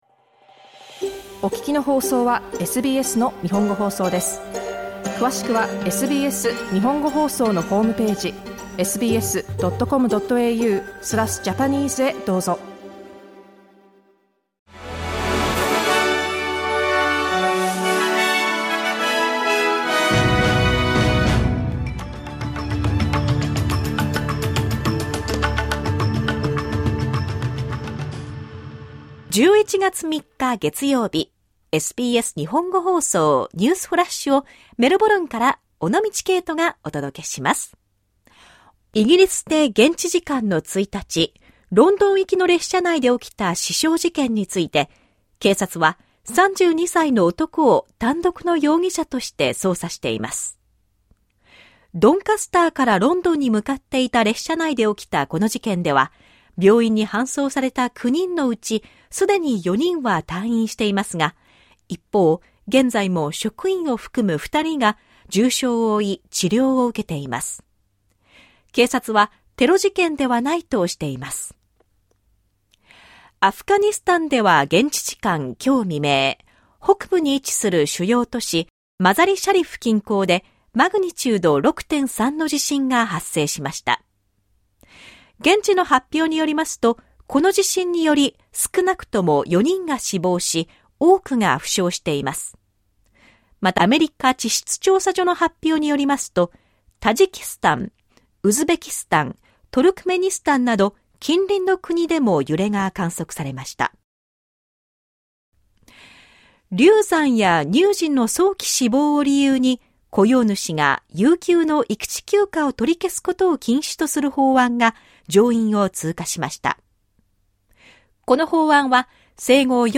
SBS日本語放送ニュースフラッシュ 11月3日 月曜日